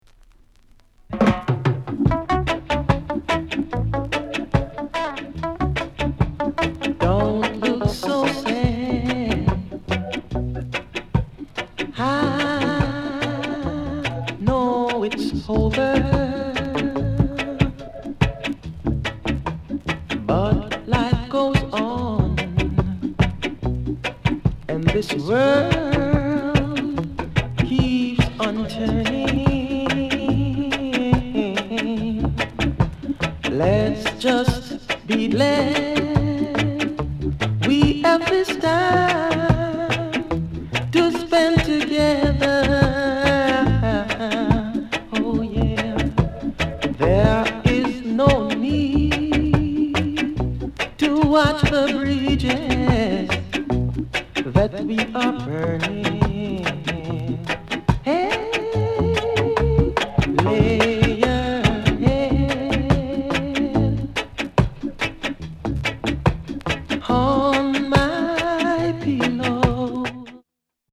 CONDITION A SIDE VG